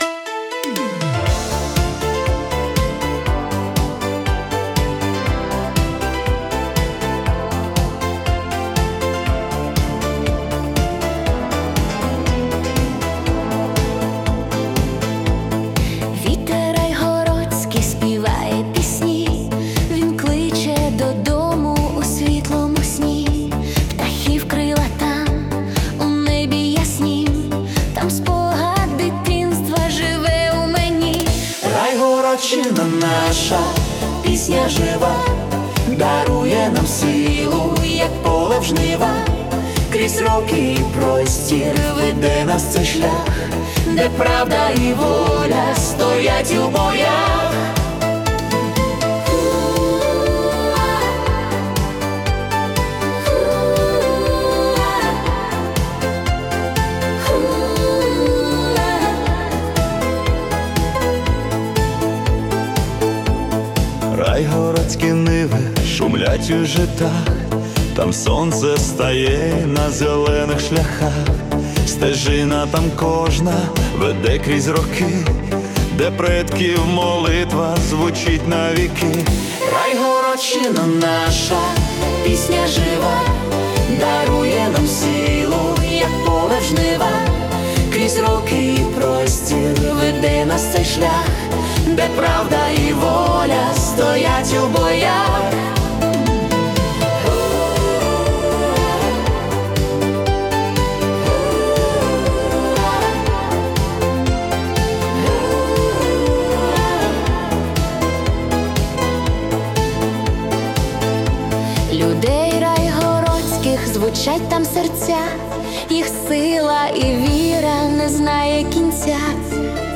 🎵 Жанр: Italo Disco / Pop-Ballad
це мелодійне та натхненне Italo Disco (120 BPM)